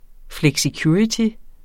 Udtale [ flεgsiˈkjuːɹiti ]